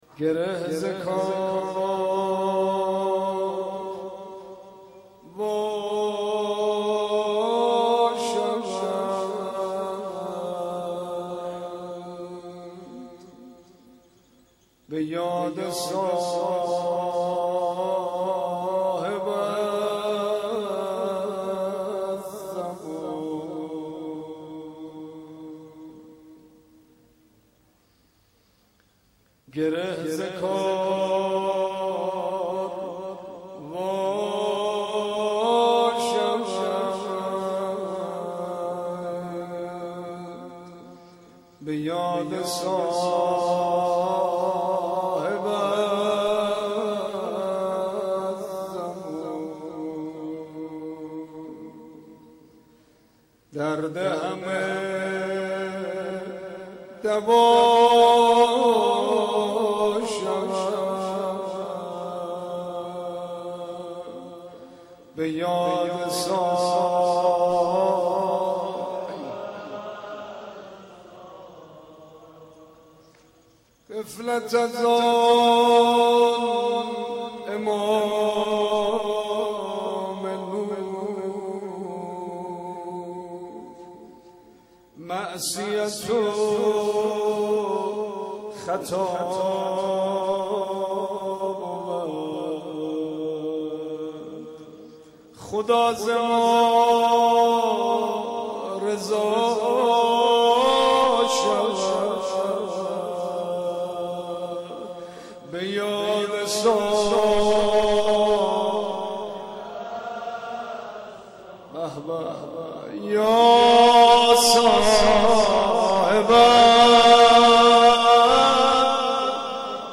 مناجات با خداوند و روضه خوانی
مناجات با امام زمان(عج).mp3